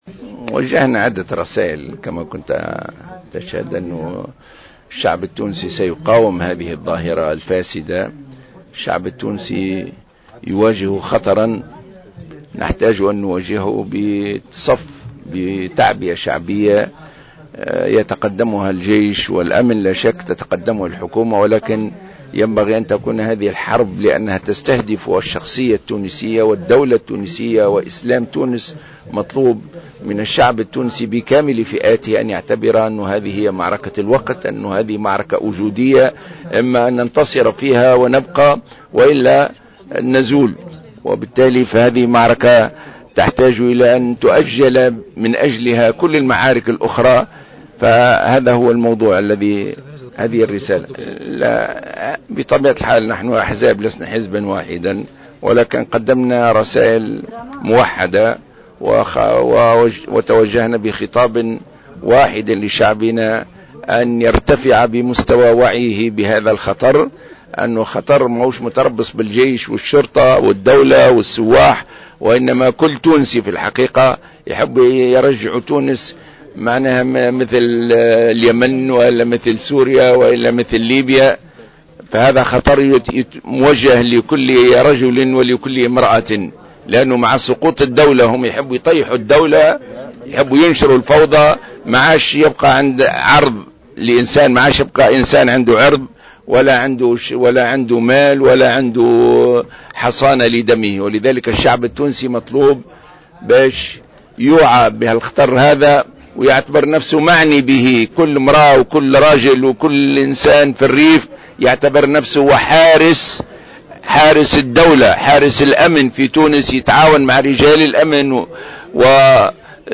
أكد رئيس حركة النهضة راشد الغنوشي خلال ندوة صحفية عقدتها الأحزاب المكونة للإئتلاف الحاكم اليوم الإثنين أن الارهاب حرب تستهدف الهوية التونسية معتبرا أنه معركة تحتاج الى أن تؤجل لأجلها كل المعارك الأخرى وفق قوله.